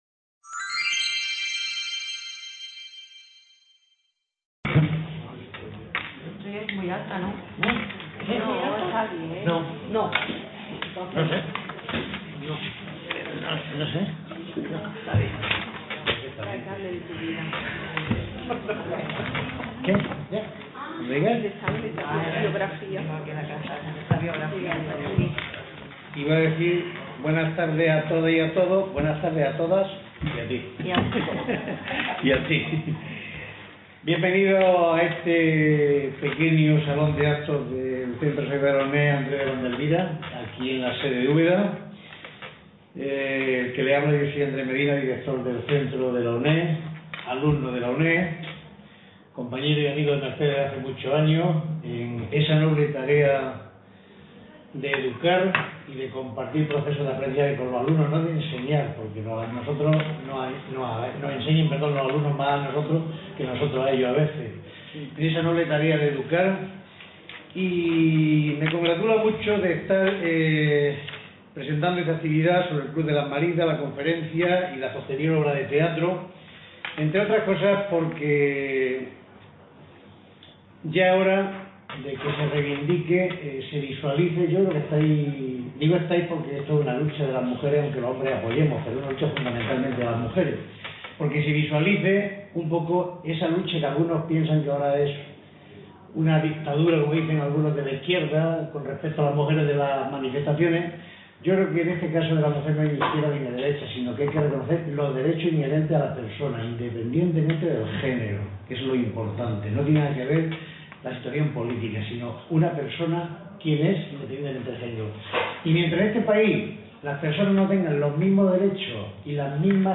El Club de las Maridas: El Lyceum Club 1926. Coloquio.